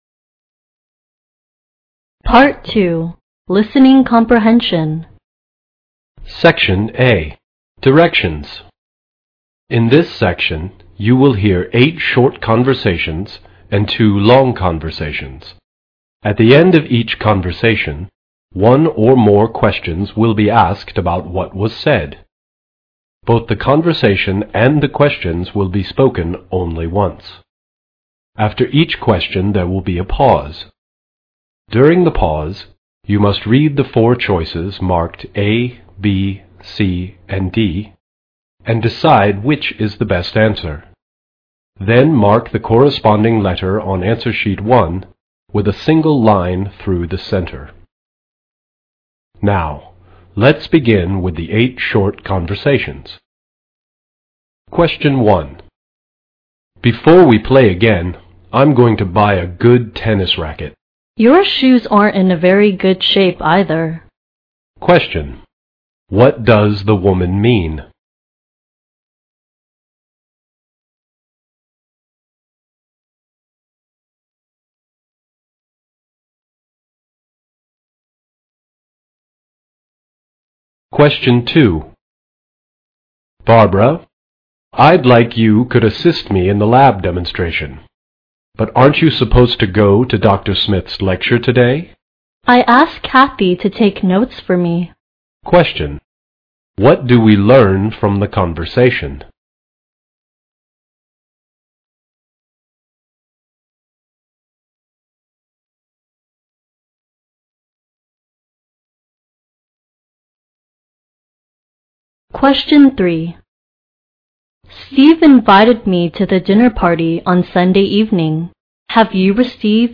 Part II Listening Comprehension (30 minutes)